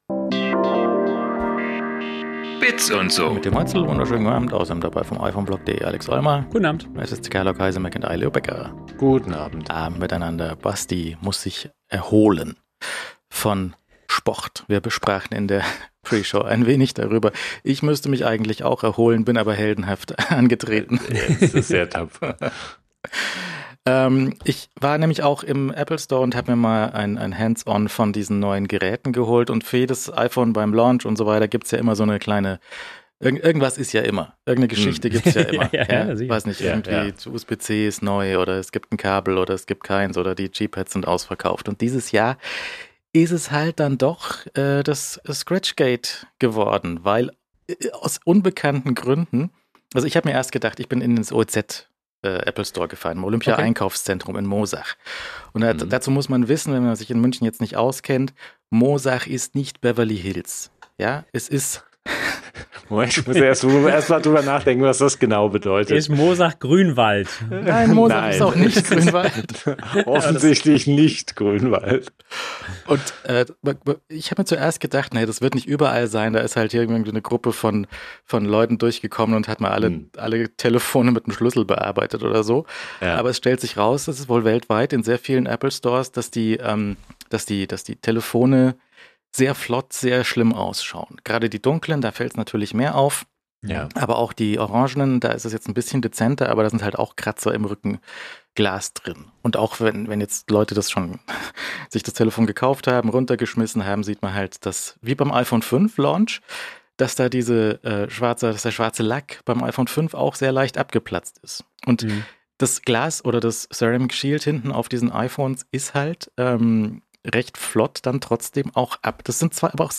Die wöchentliche Talkrunde rund um Apple, Mac, iPod + iPhone, Gadgets und so. Fast live aus München.